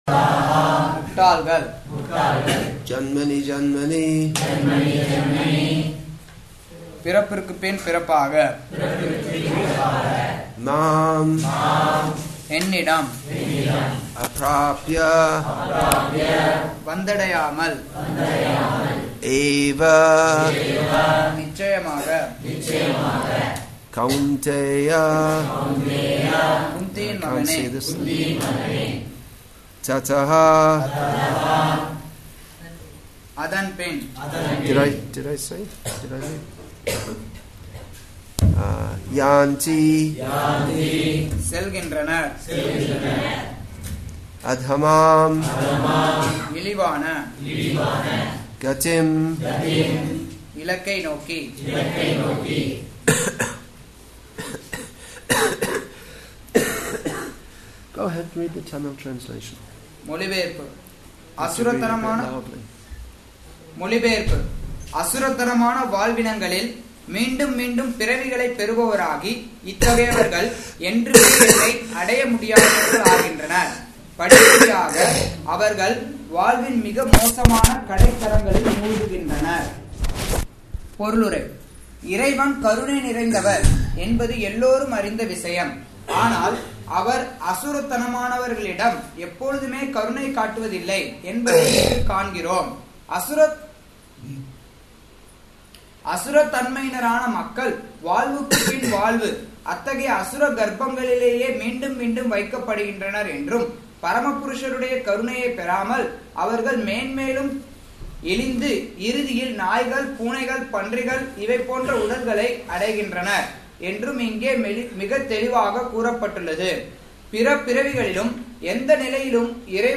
English with தமிழ் (Tamil) Translation; Vellore, Tamil Nadu , India Bhagavad-gītā 16.20